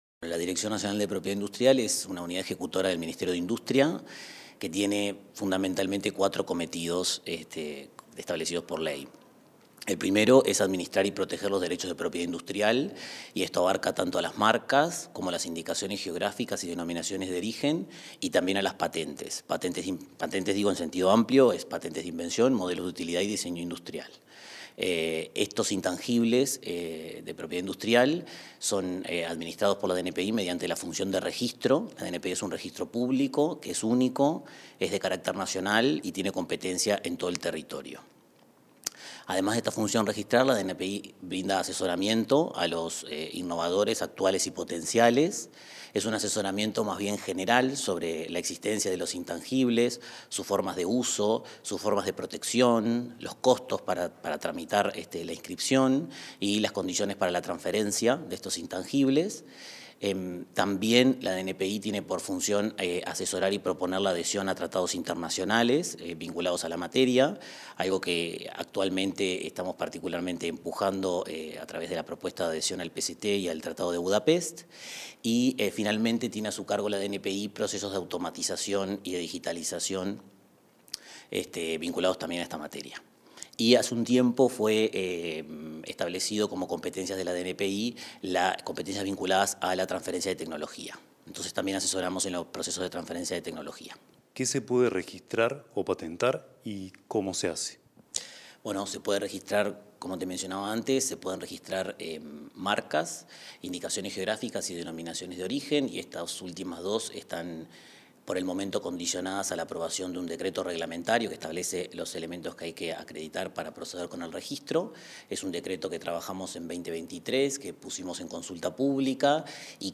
Entrevista al director nacional de Propiedad Industrial, Santiago Martínez